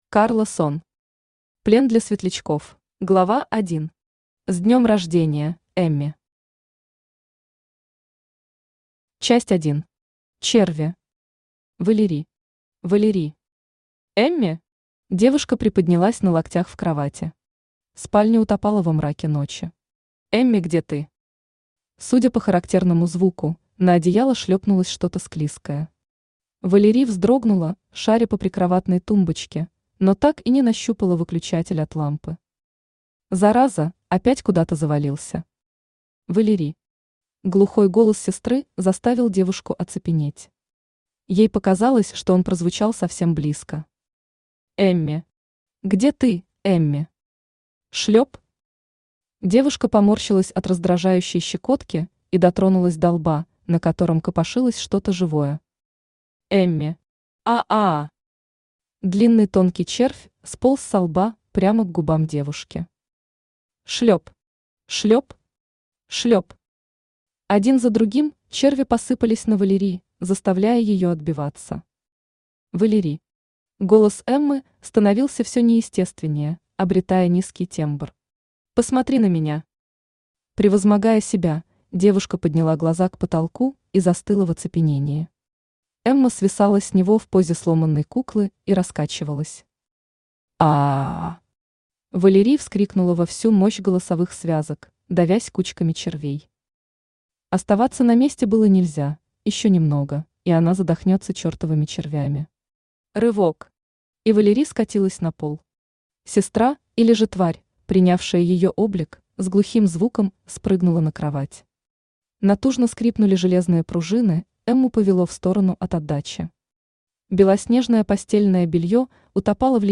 Аудиокнига Плен для светлячков | Библиотека аудиокниг
Прослушать и бесплатно скачать фрагмент аудиокниги